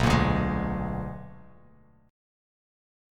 BmM13 chord